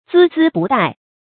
孜孜不怠 注音： ㄗㄧ ㄗㄧ ㄅㄨˋ ㄉㄞˋ 讀音讀法： 意思解釋： 勤勉努力，毫不懈怠。